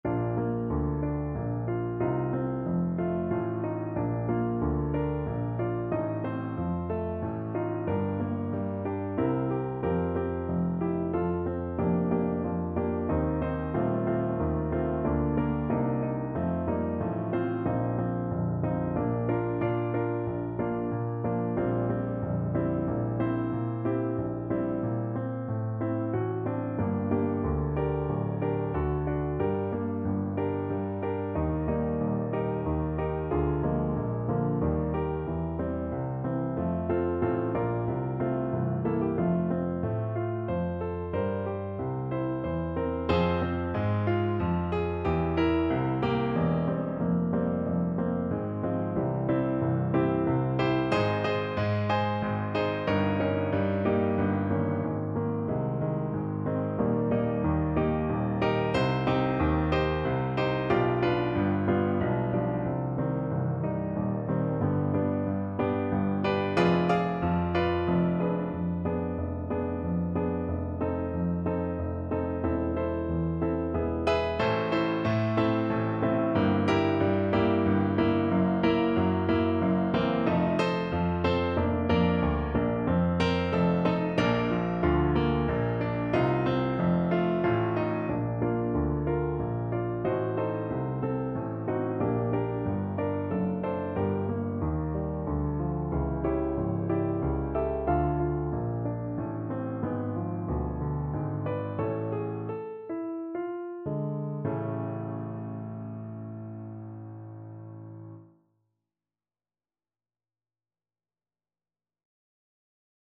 3/4 (View more 3/4 Music)
~ = 92 Larghetto
Classical (View more Classical Trumpet Music)